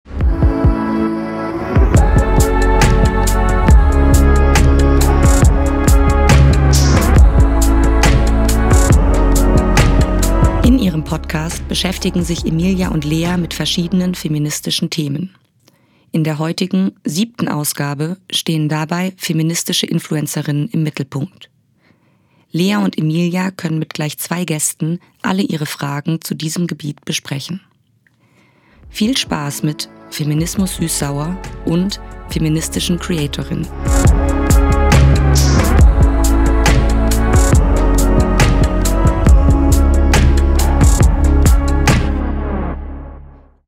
Teaser_585.mp3